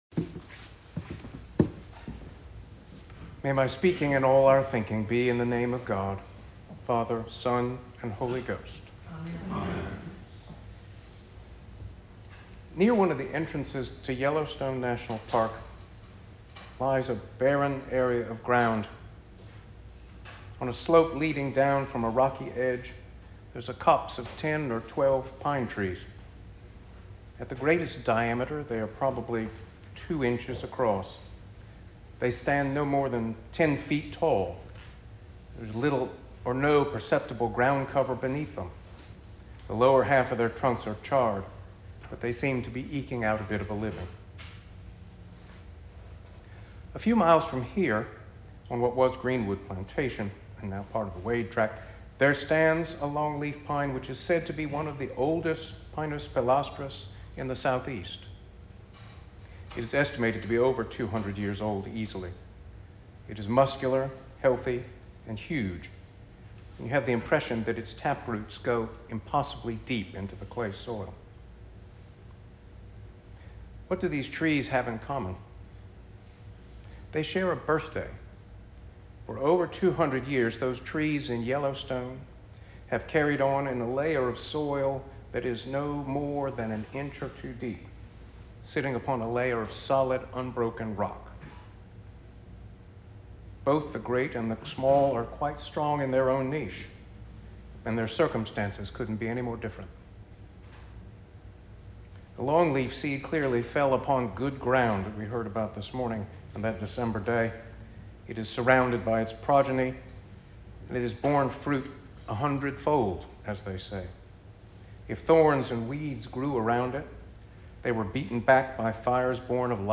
Sermon for Sexagesima 02.23.2025